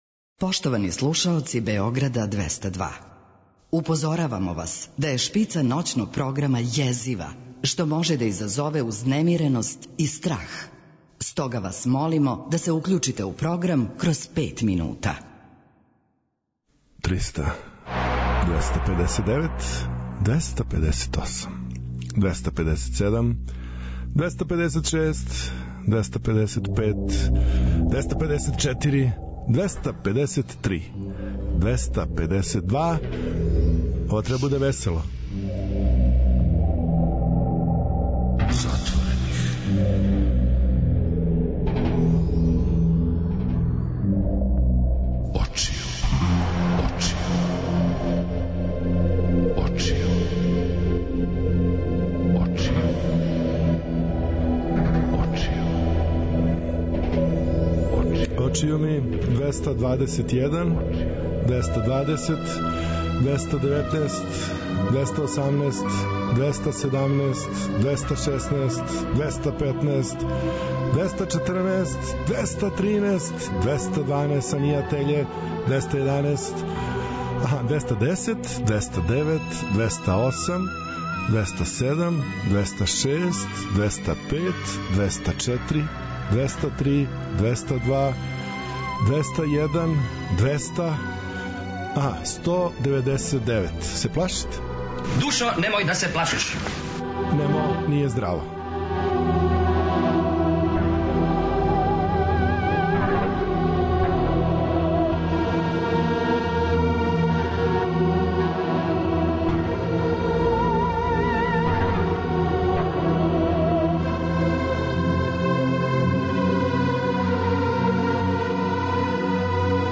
Ново издање емисије Златне године, музика 60-тих и 70-тих, када је музика хтела да промени свет, а свет је променио музику.